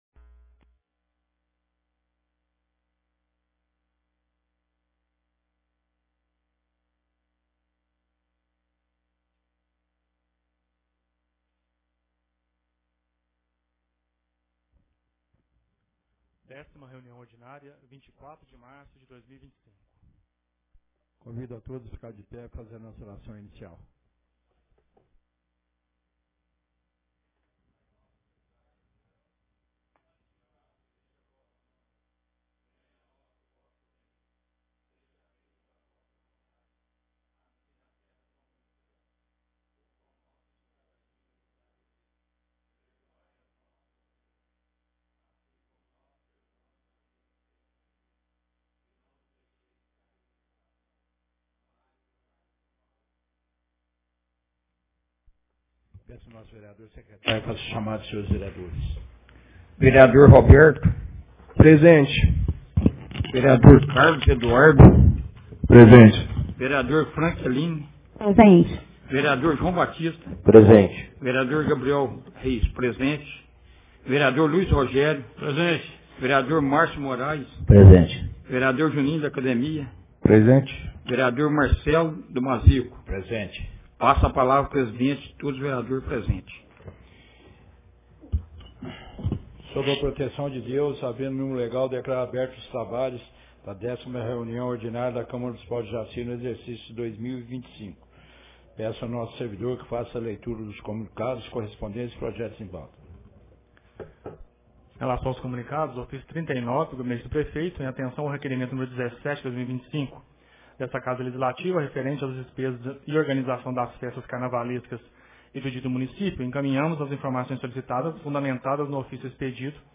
Ata da 10ª Reunião Ordinária de 2025 — Câmara Municipal